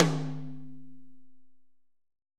Index of /90_sSampleCDs/AKAI S6000 CD-ROM - Volume 3/Drum_Kit/AMBIENCE_KIT3
R.AMBTOMH2-S.WAV